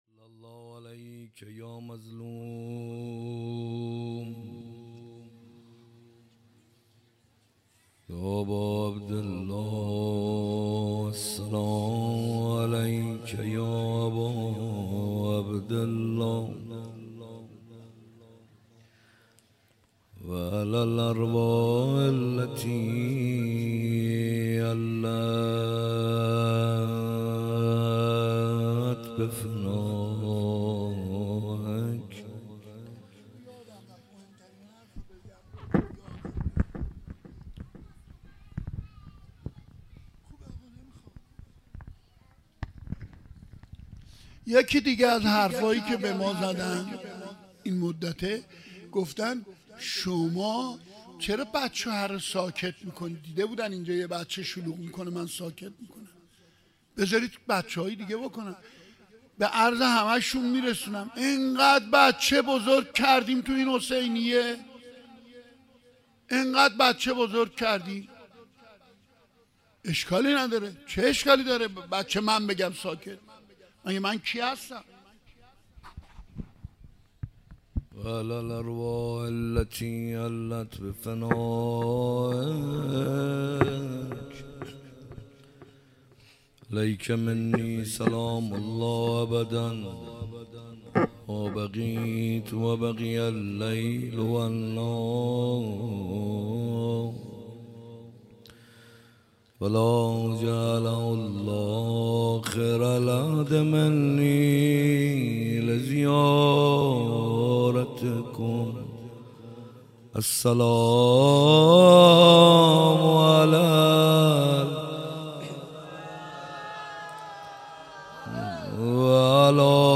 حسینیه کربلا